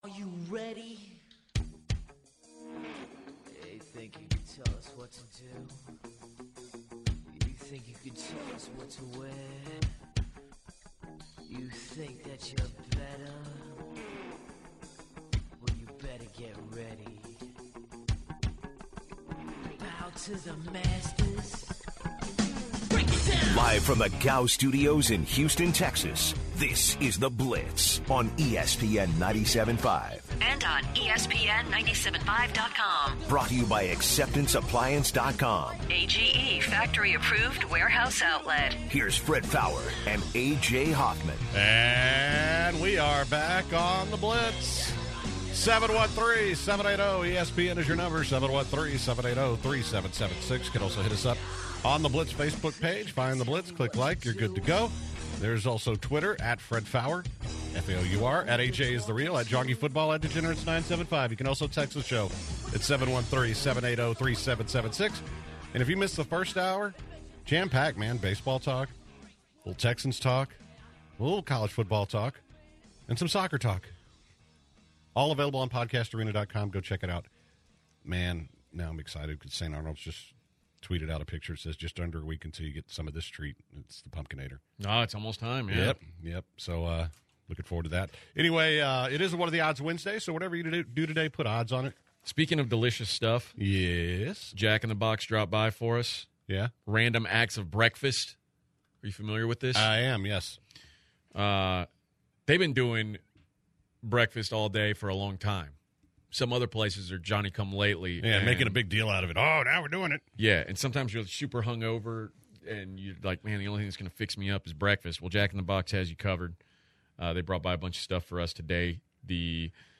The guys answer some "what are the odds" questions from callers.